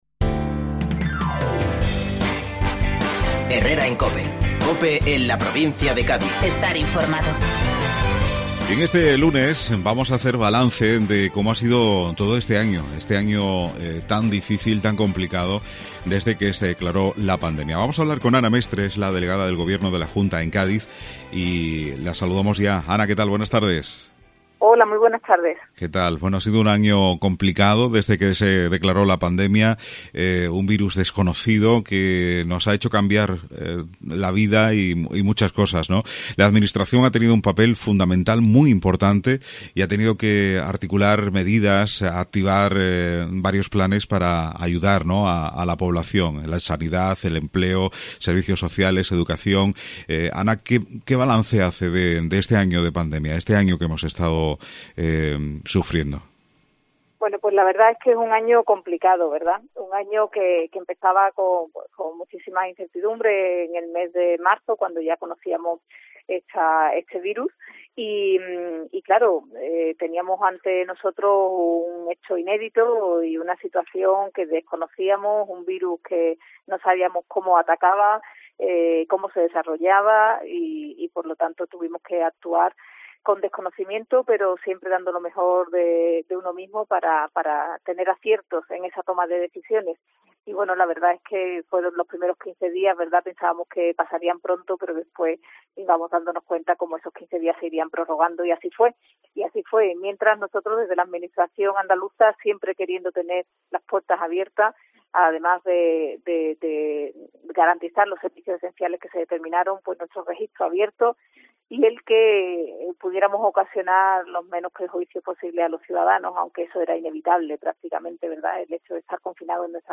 Ana Mestre hace balance del año de pandemia en los micrófonos de COPE